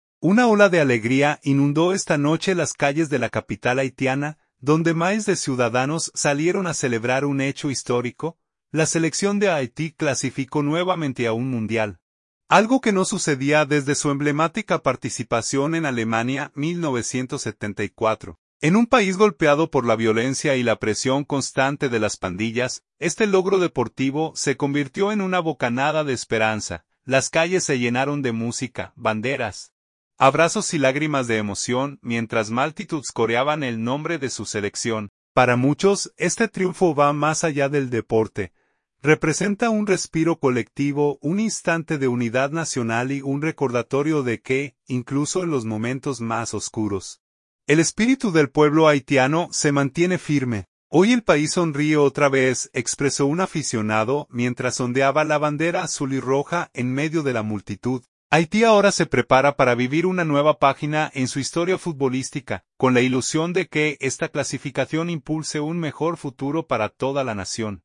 PUERTO PRÍNCIPE (HAITÍ), 19/11/2025.- Aficionados celebran esta noche en Puerto Príncipe la clasificación de Haití para el Mundial de Estados Unidos, México y Canadá 2026 al quedar primera en su grupo de la Concacaf.
Las calles se llenaron de música, banderas, abrazos y lágrimas de emoción, mientras multitudes coreaban el nombre de su selección.